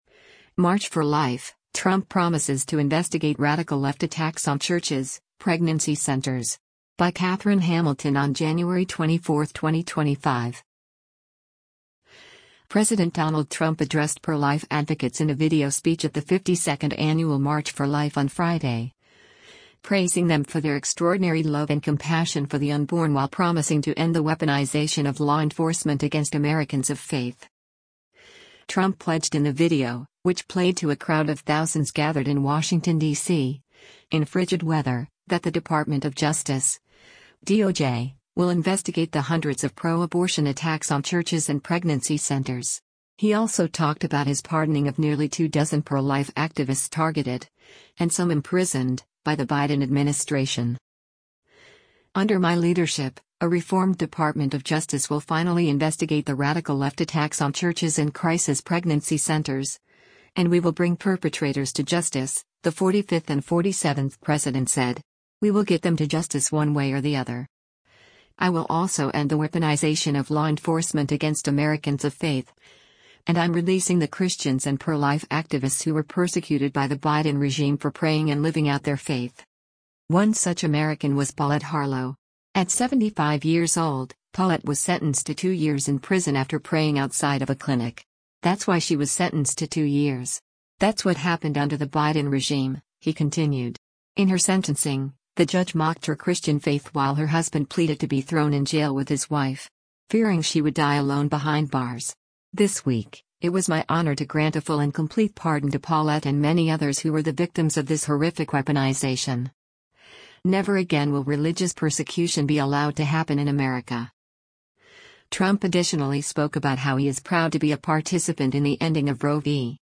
President Donald Trump addressed pro-life advocates in a video speech at the 52nd annual March for Life on Friday, praising them for their “extraordinary love and compassion for the unborn” while promising to “end the weaponization of law enforcement against Americans of faith.”
Trump pledged in the video — which played to a crowd of thousands gathered in Washington, DC, in frigid weather — that the Department of Justice (DOJ) will investigate the hundreds of pro-abortion attacks on churches and pregnancy centers.